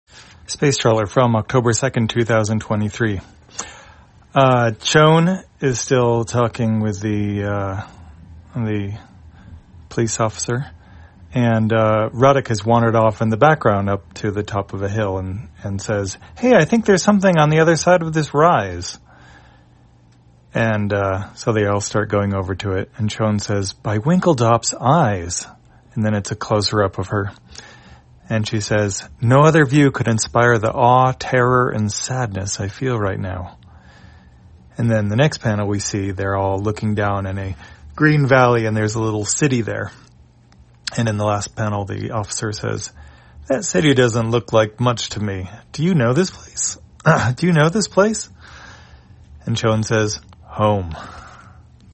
Spacetrawler, audio version For the blind or visually impaired, October 2, 2023.